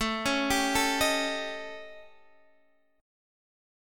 A7b5 Chord
Listen to A7b5 strummed